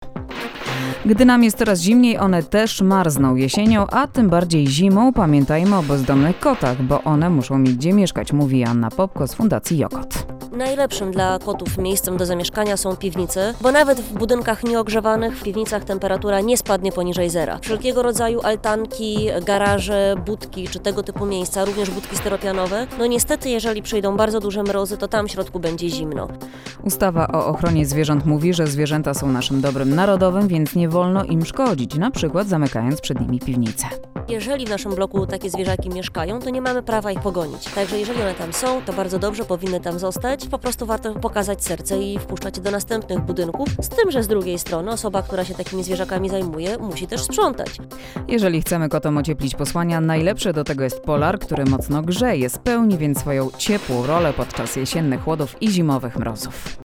Nagranie zostało wyemitowane w Programie 4 Polskiego Radia 12 października 2015 r.